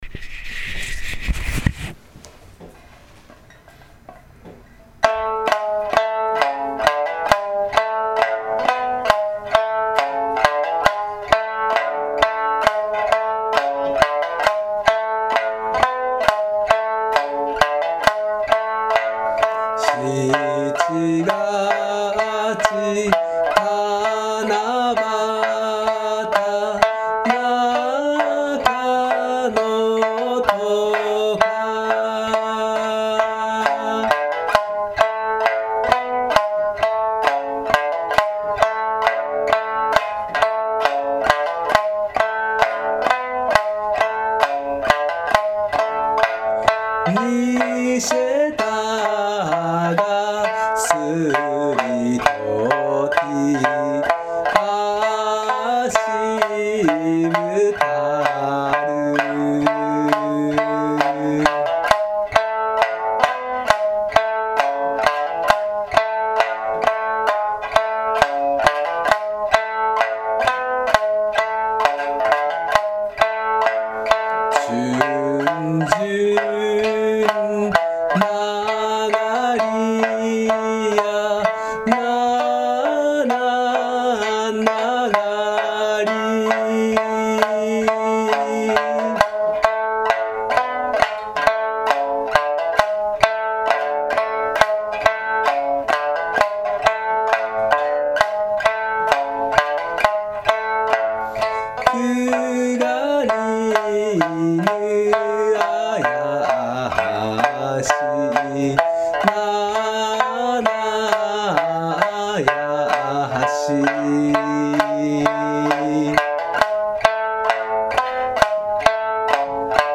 そんな訳で三線でちょっと歌って見たいと思います。